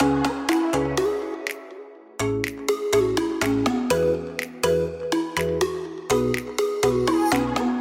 Типа маримба pluck (tropical house)
Ищу этот модный и популярный звук, похож на маримбу, но очень синтетическую.
Хочу уточнить, что я не имею ввиду мелодию на панфлейте, только pluck разведённый по сторонам который;)